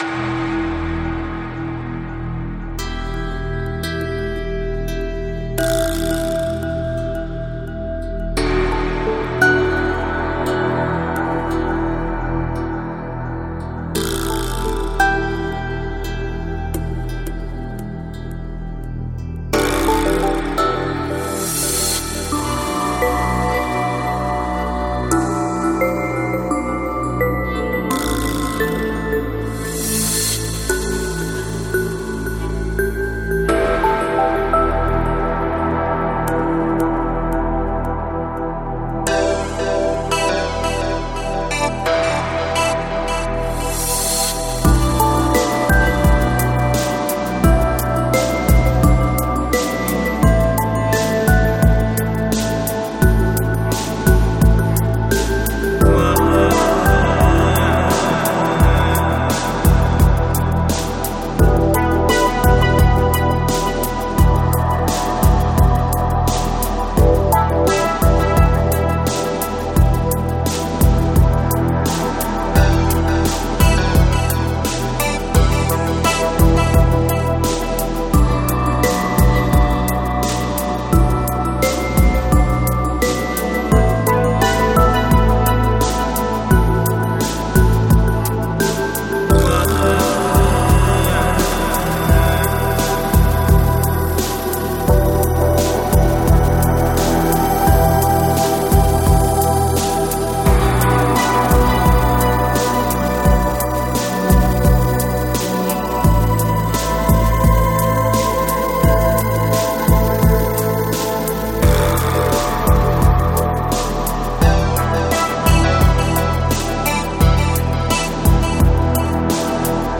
Жанр: Ambient